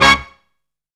BREAKIN HIT.wav